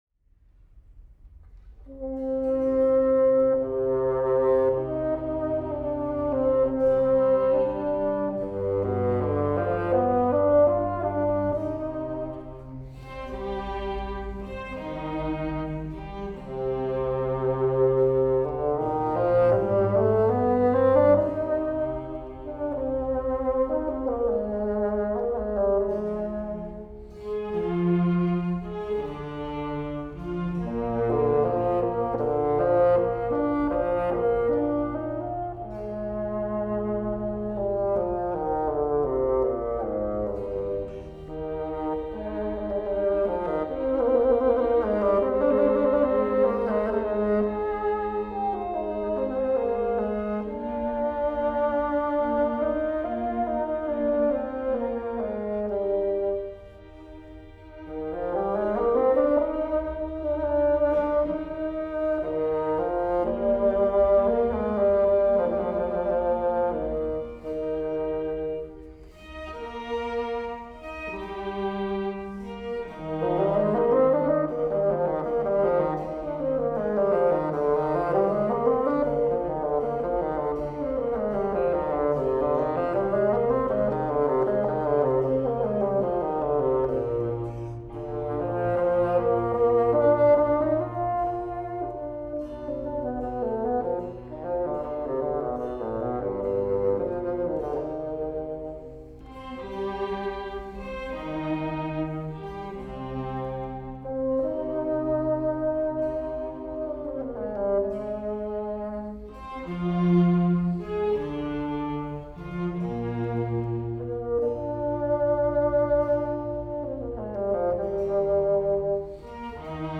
DLA koncert live concert